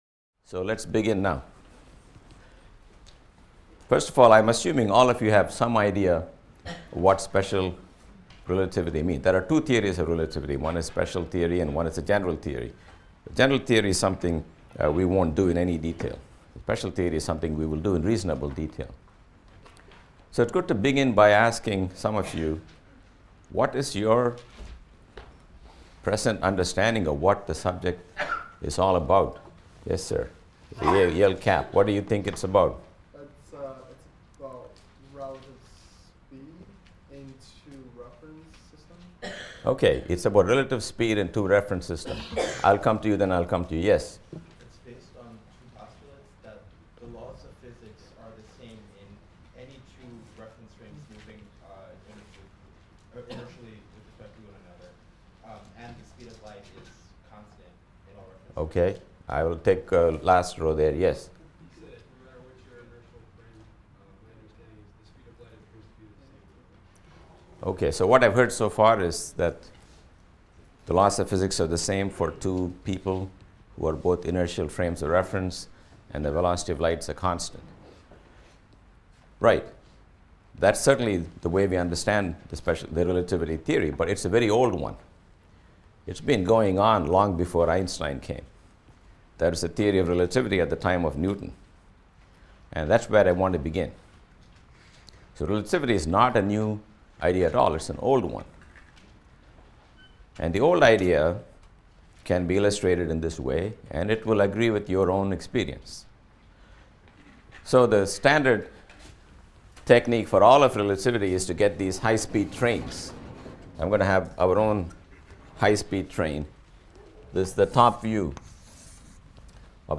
PHYS 200 - Lecture 12 - Introduction to Relativity | Open Yale Courses